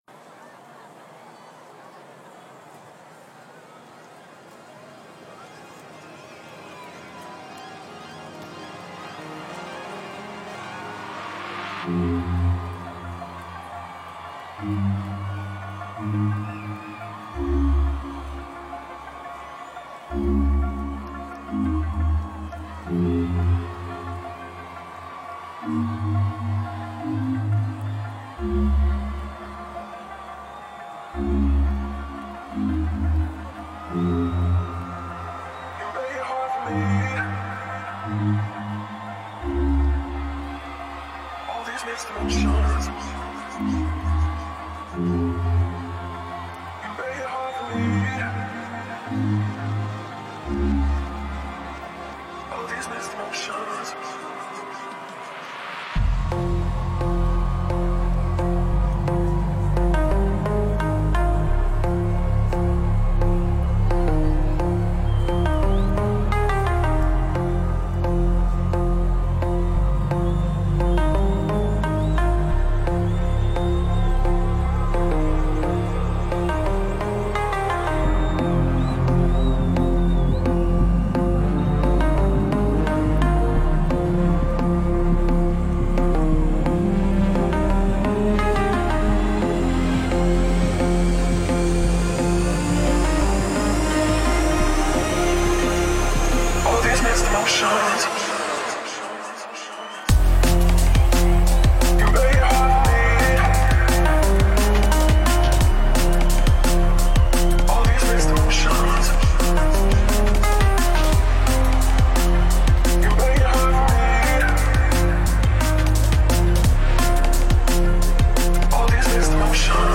Liveset
Genre: Trap